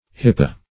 Search Result for " hippa" : The Collaborative International Dictionary of English v.0.48: Hippa \Hip"pa\, Hippe \Hip"pe\, n. (Zool.) A genus of marine decapod crustaceans, which burrow rapidly in the sand by pushing themselves backward; -- called also bait bug .